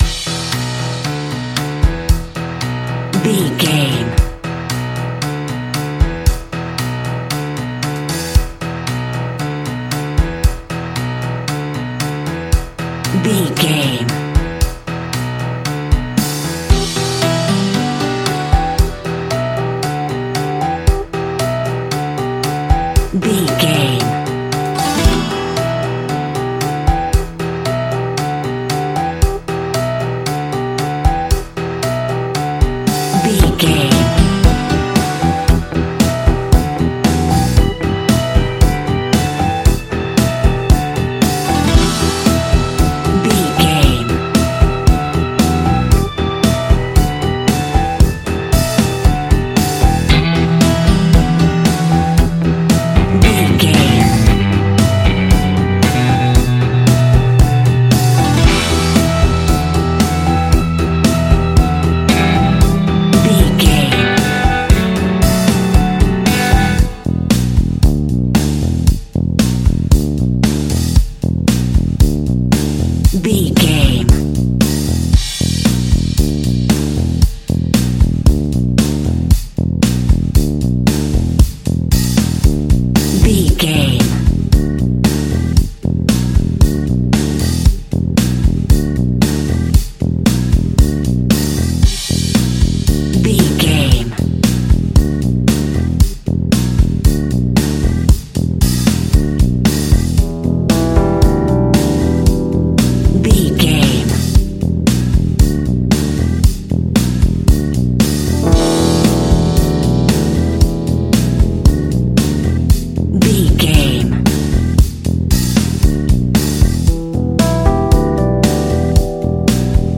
Aeolian/Minor
pop rock
indie pop
fun
energetic
uplifting
motivational
drums
bass guitar
piano
electric guitar